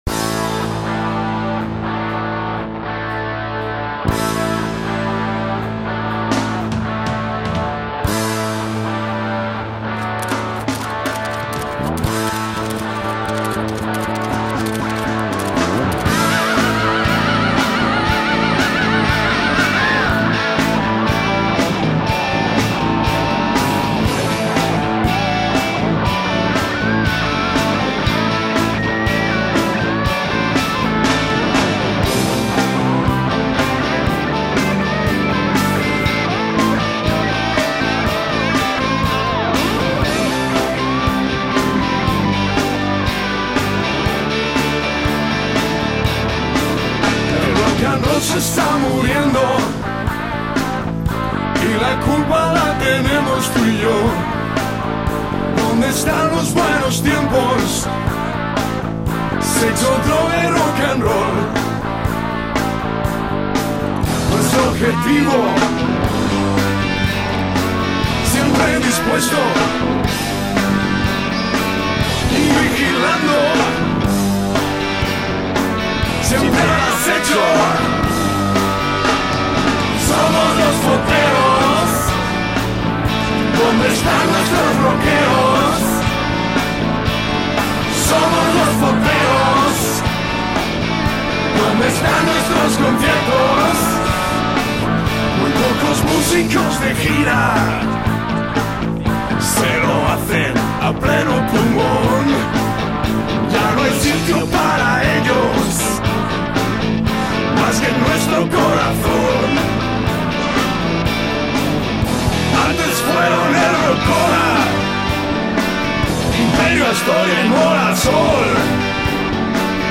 interpretada por esos mismos fotógrafos
bajo
guitarras
batería